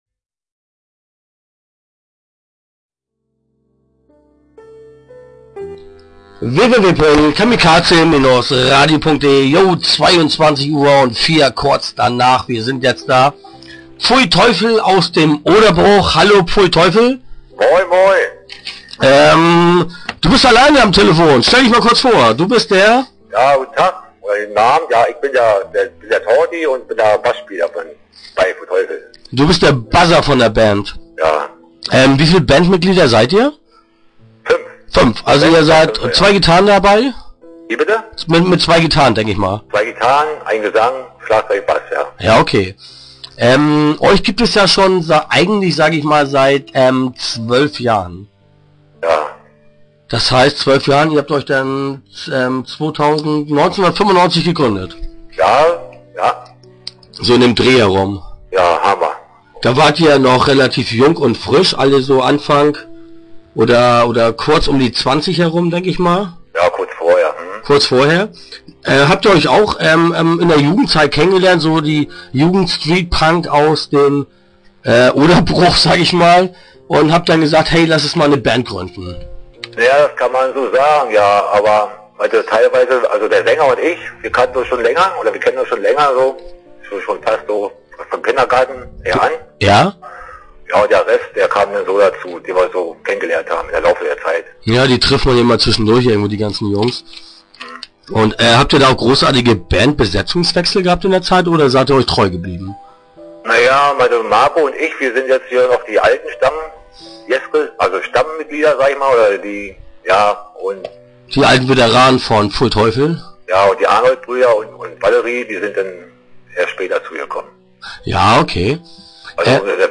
Start » Interviews » Pfui Teufel